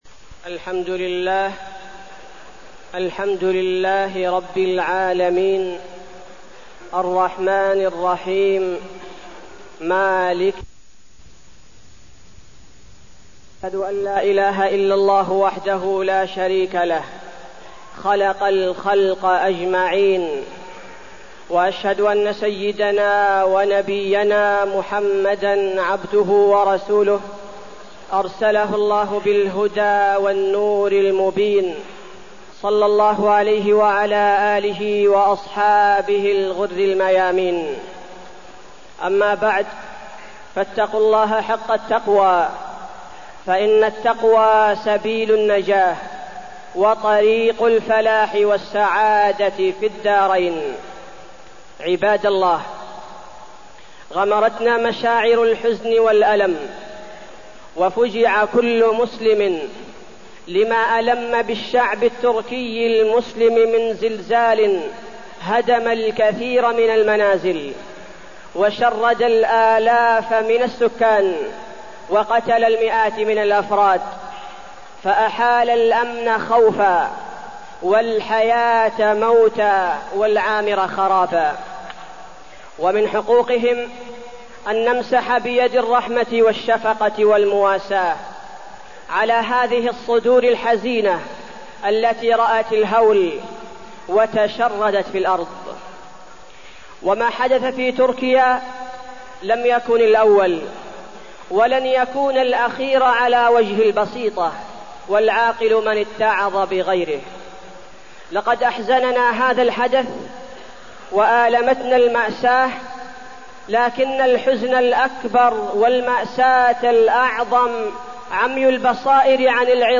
تاريخ النشر ١١ شعبان ١٤٢٠ هـ المكان: المسجد النبوي الشيخ: فضيلة الشيخ عبدالباري الثبيتي فضيلة الشيخ عبدالباري الثبيتي الزلازل